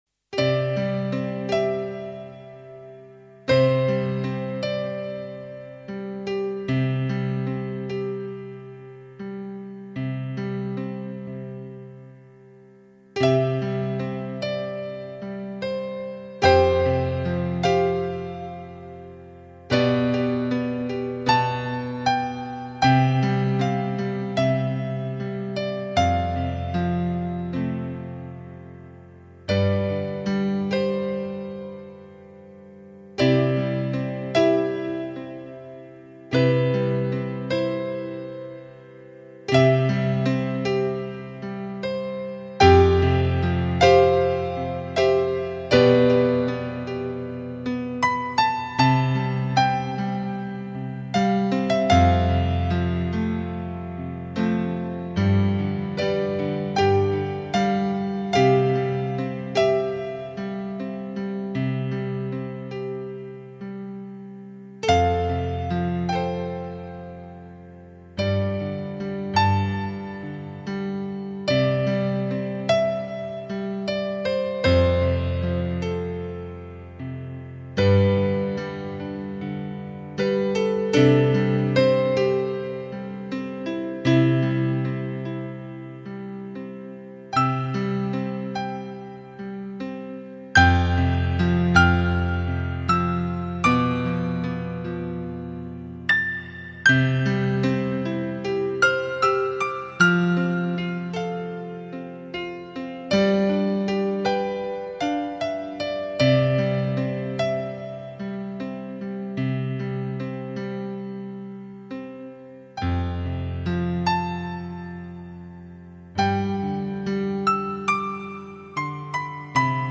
Genre Música para El Alma